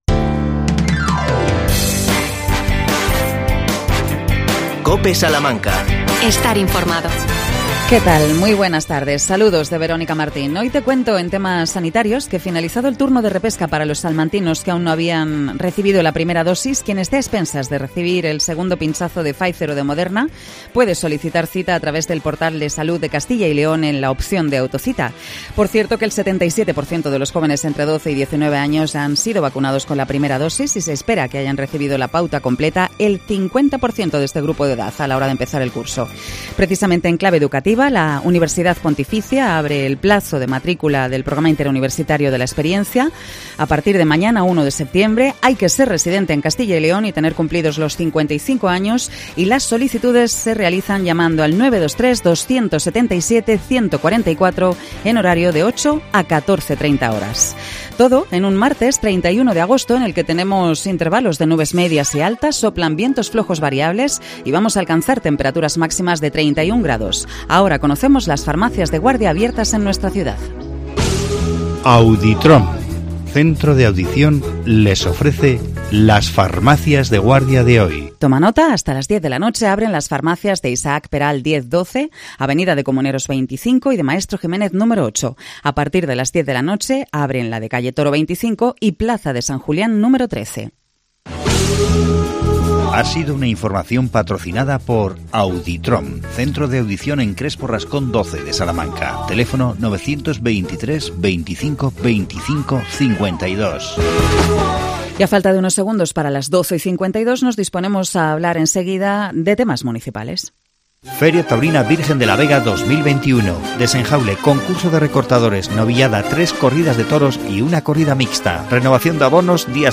AUDIO: La concejala Ana Suárez informa sobre el nuevo servicio municipal de acogida para animales abandonados.